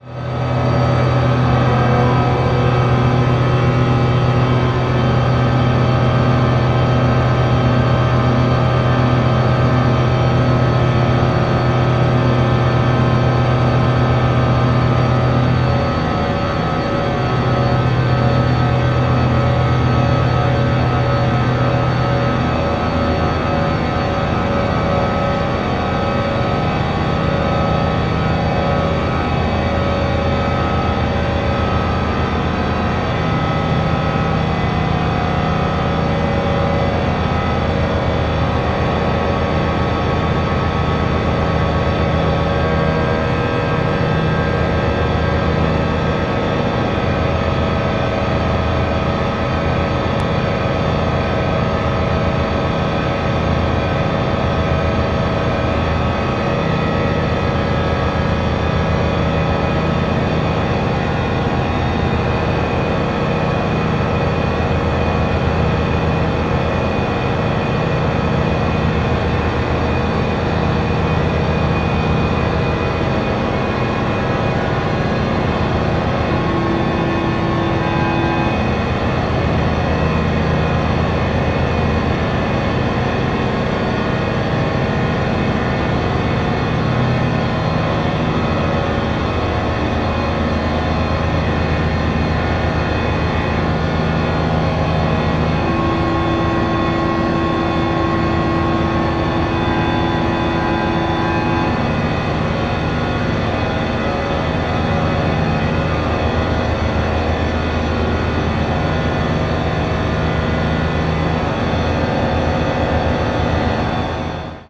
高密度電子音を軸に唸る様に進行するサマは、まさに強烈な聴覚体験を与えてくれる激高内容。